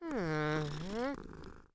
Npc Catpurr Sound Effect
npc-catpurr-2.mp3